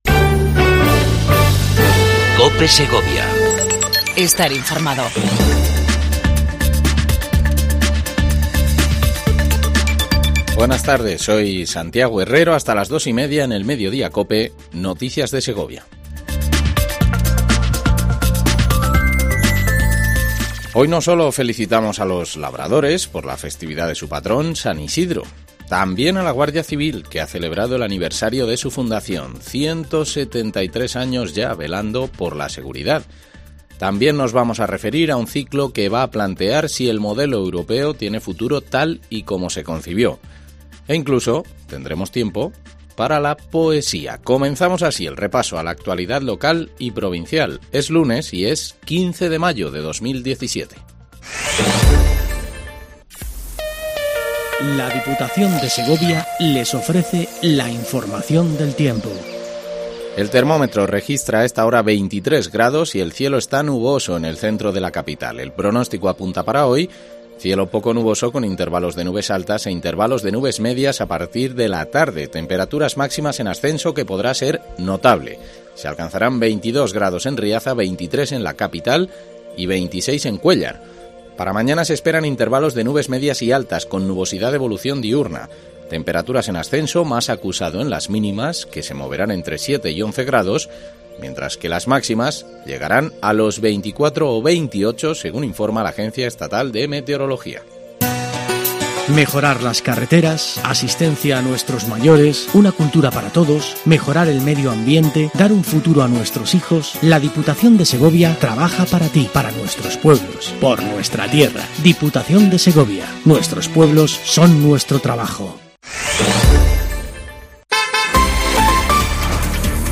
INFORMATIVO MEDIODIA COPE EN SEGOVIA 15 05 17